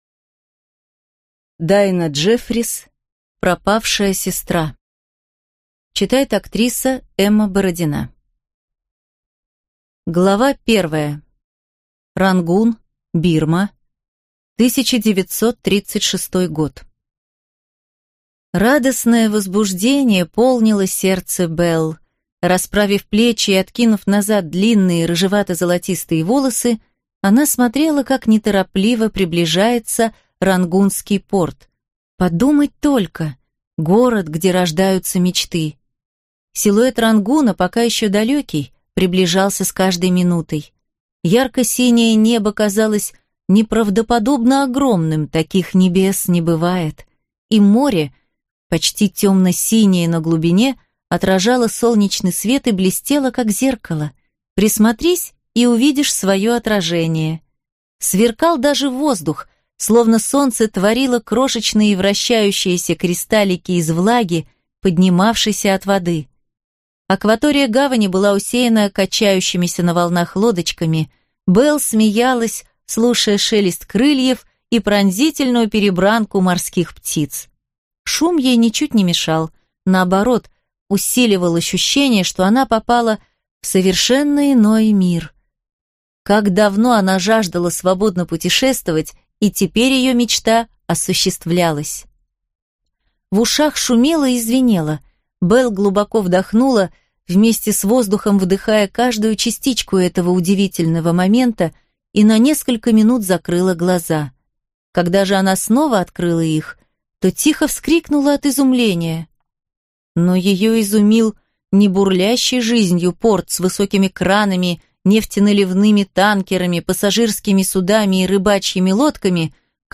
Аудиокнига Пропавшая сестра | Библиотека аудиокниг